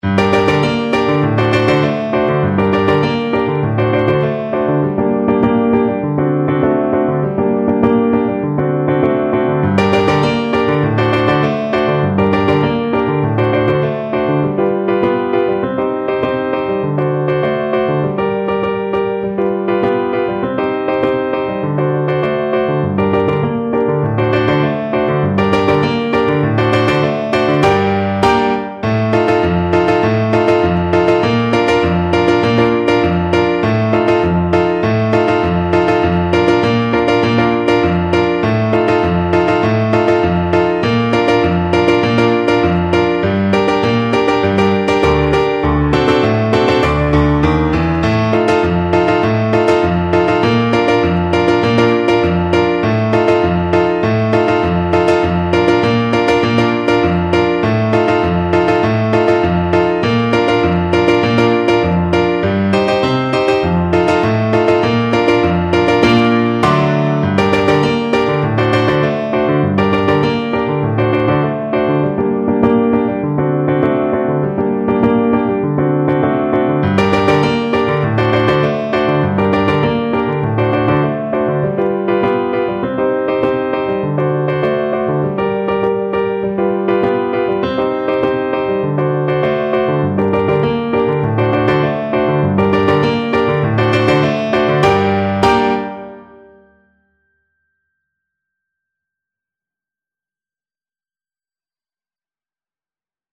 2/4 (View more 2/4 Music)
World (View more World Clarinet Music)
Brazilian